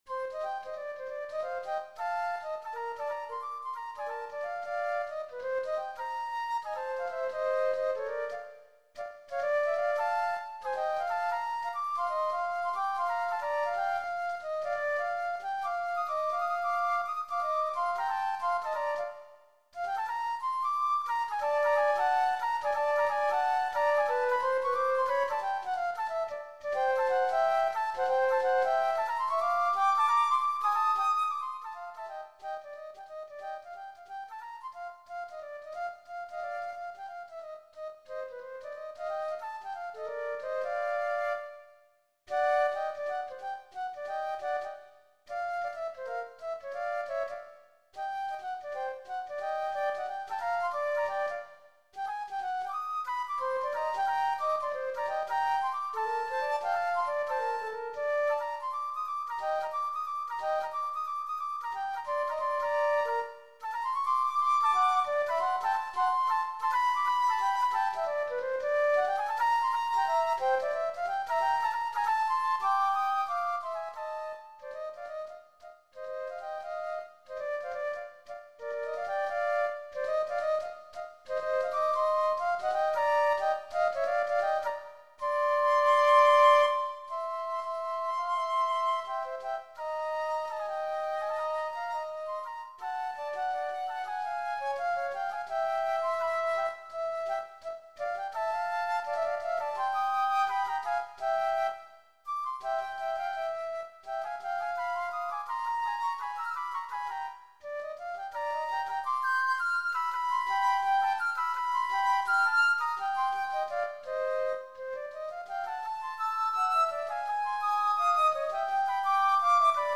Voicing: Flute Duet